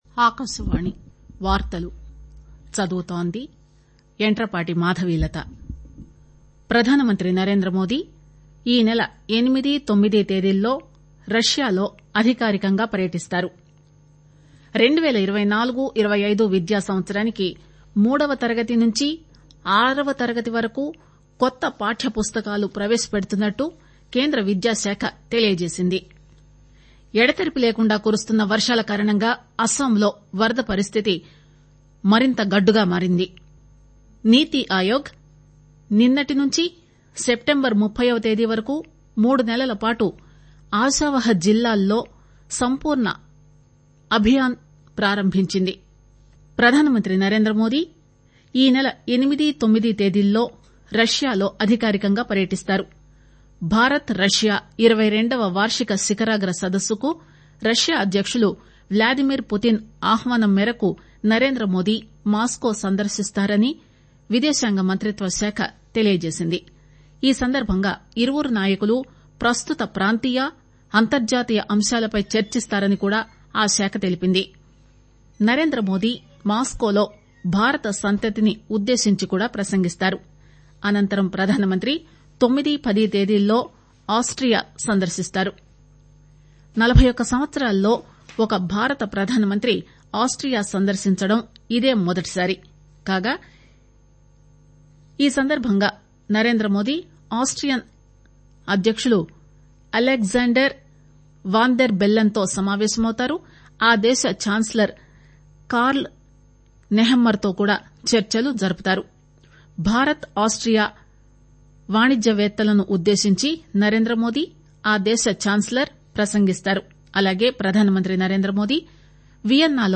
NATIONAL-TELUGU-NEWS-BULLETIN-0705-HOURS.mp3